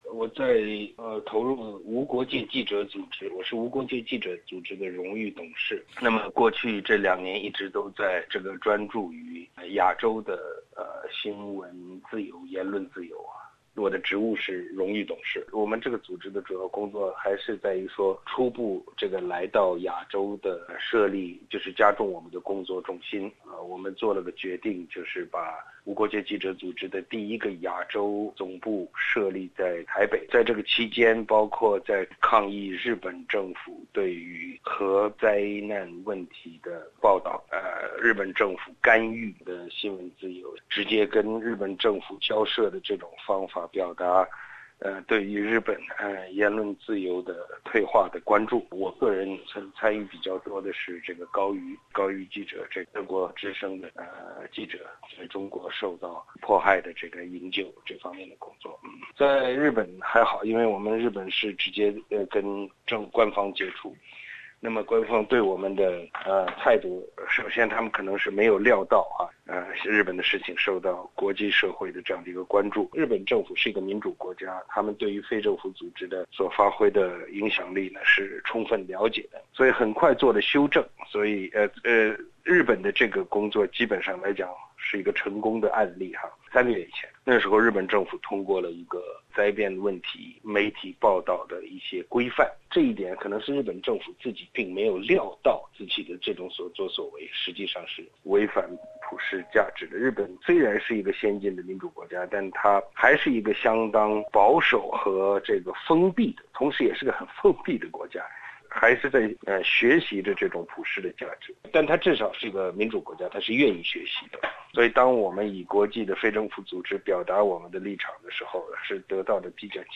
吾尔开希接受本台采访，参与SBS普通话节目6月份的系列话题：价值观大激辩。